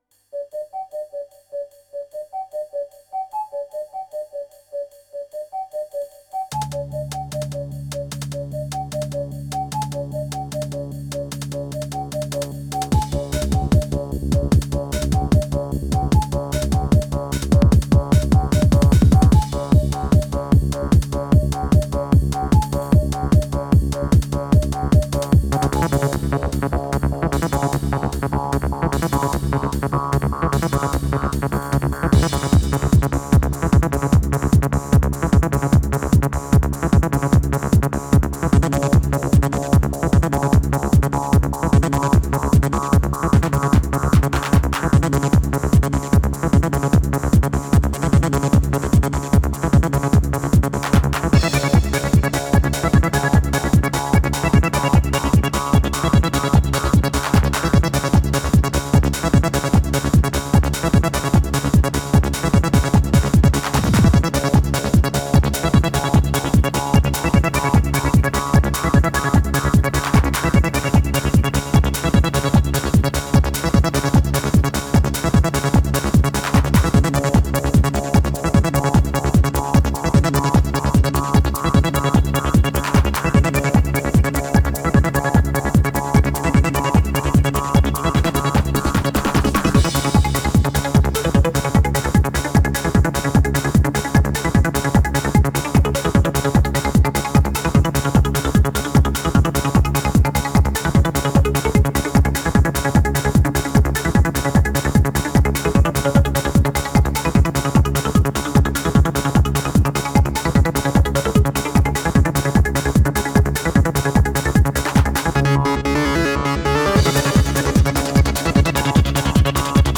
Очень ритмичная (открыта)